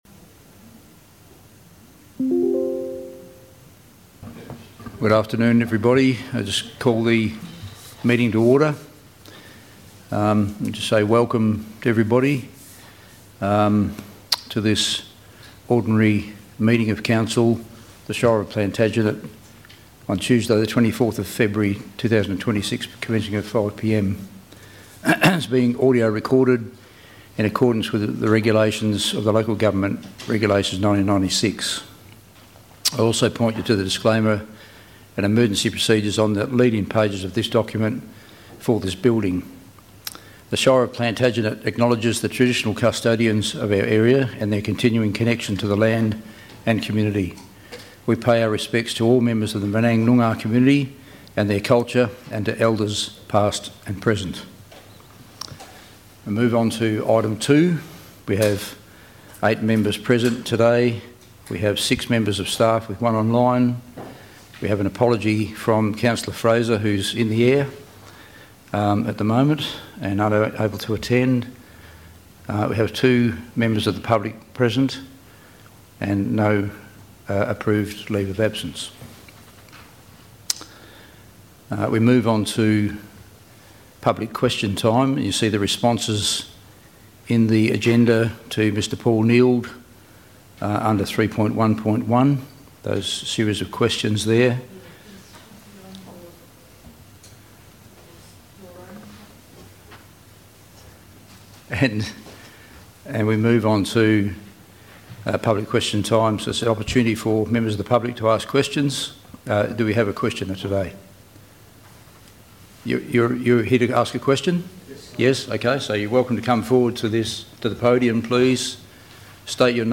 Ordinary Council Meeting - Tuesday 24 February 2026 - 5:00pm » Shire of Plantagenet